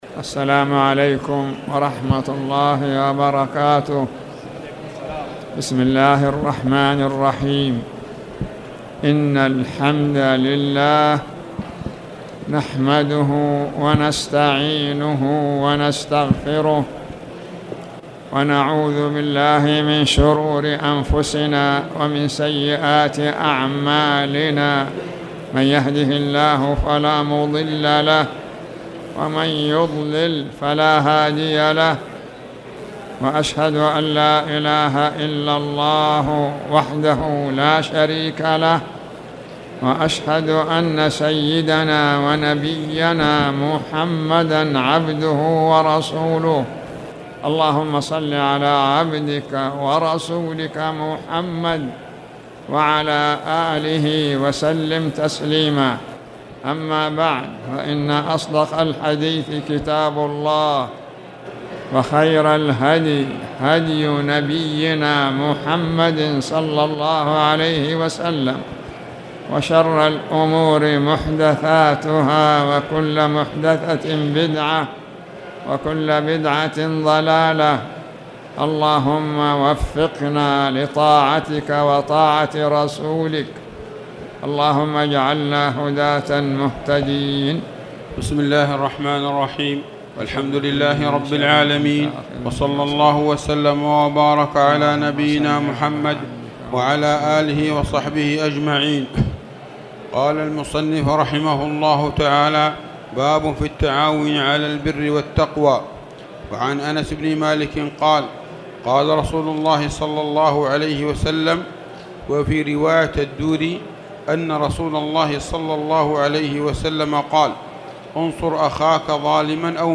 تاريخ النشر ٤ شعبان ١٤٣٨ هـ المكان: المسجد الحرام الشيخ